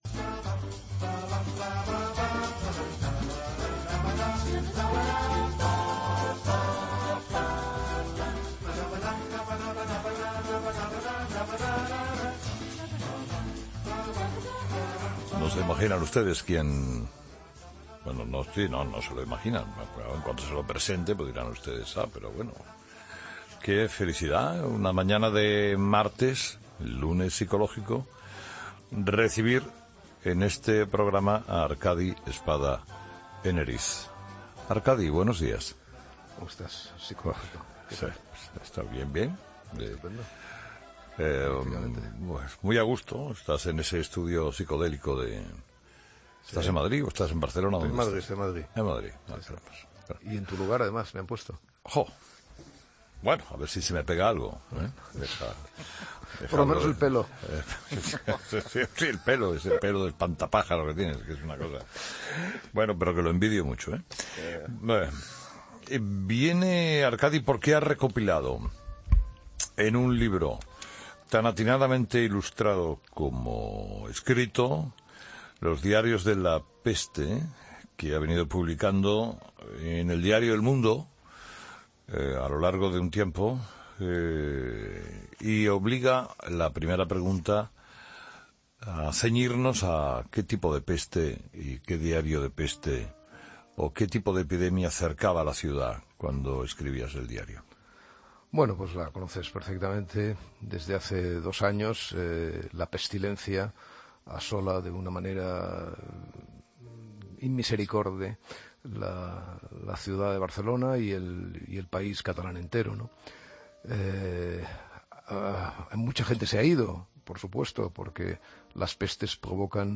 Escucha la entrevista a Arcadi Espada en 'Herrera en COPE'